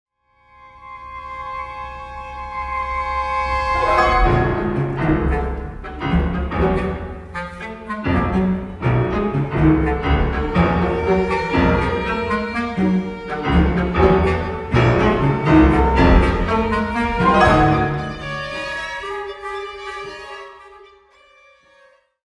FOR CHAMBER ENSEMBLE
9´             fl, cl, pno, vln, vla, vcl